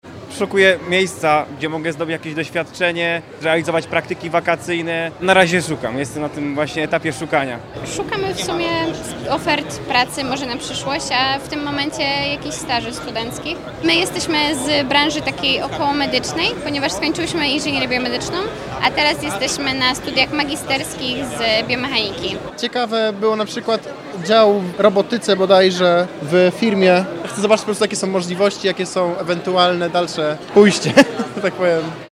Zapytaliśmy studentów, w jakim celu pojawili się na targach i czy znaleźli coś dla siebie.